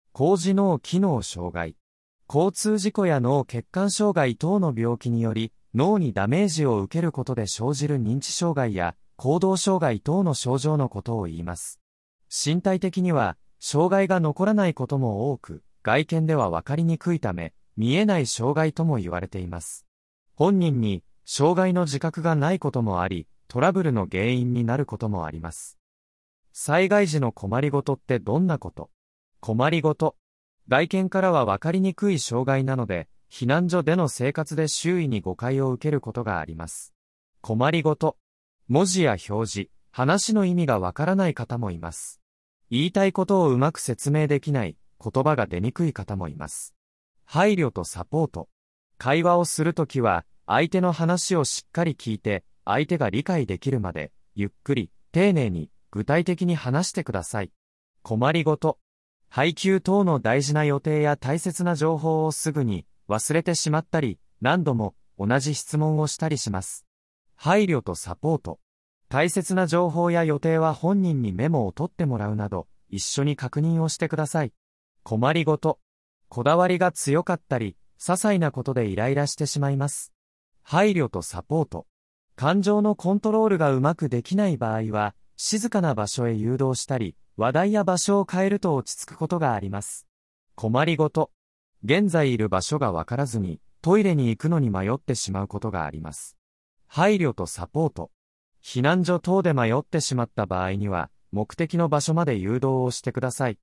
なお、録音版は合成音声により製作しております。